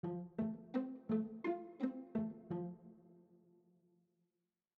RythmGame/SimpleGame/media/chords/variation2/F.mp3 at 785453b009a8a8e0f5aa8fdd36df7abdc11622fa